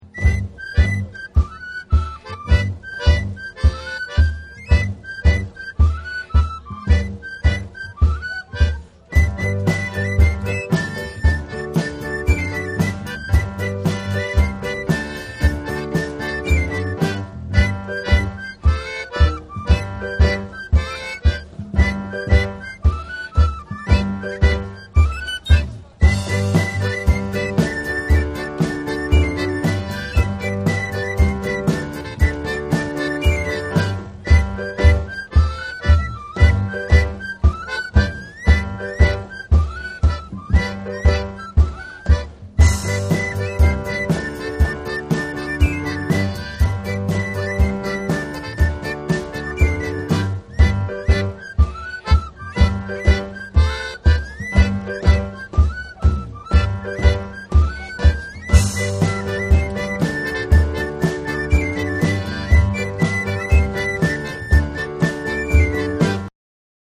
• Musiques de Balèti du Bal des Feux de la St Jean d’Aix :